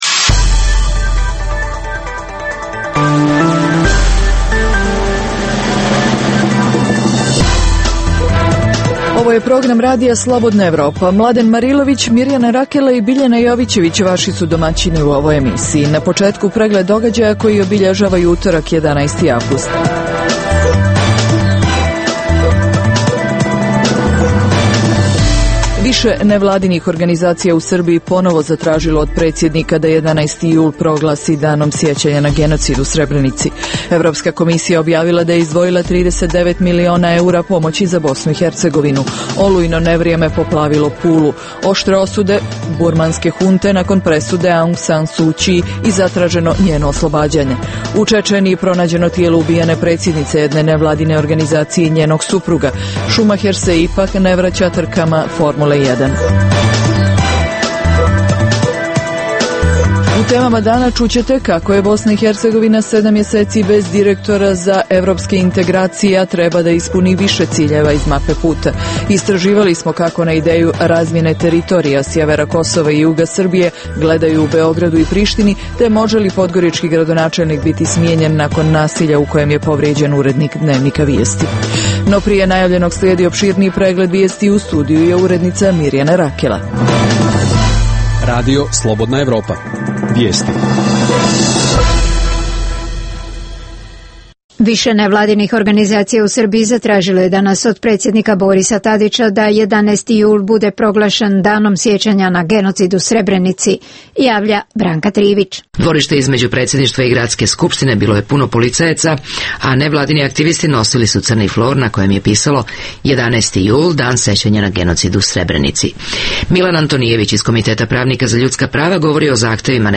Prvih pola sata emisije sadrži regionalne i vijesti iz svijeta, te najaktuelnije i najzanimljivije teme o dešavanjima u zemljama regiona i teme iz svijeta. Preostalih pola sata emisije, nazvanih “Dokumenti dana” sadrži analitičke teme, intervjue i priče iz života.